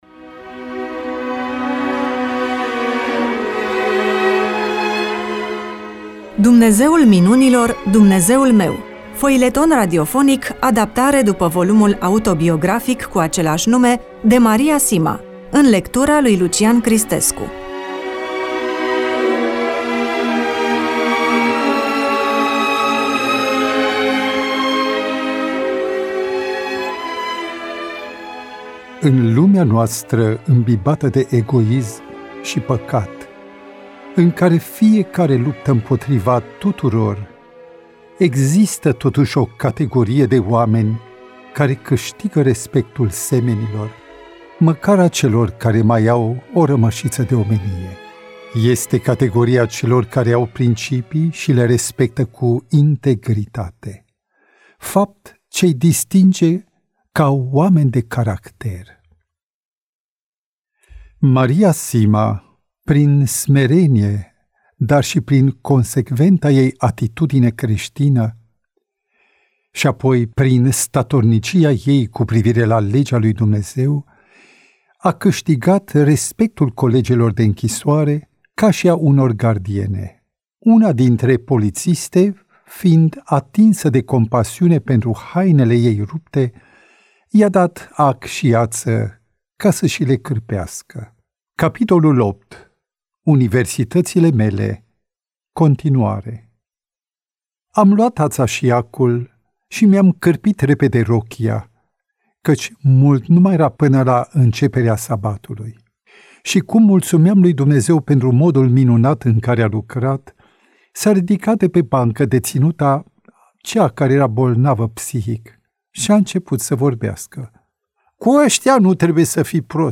EMISIUNEA: Roman foileton DATA INREGISTRARII: 02.01.2026 VIZUALIZARI: 38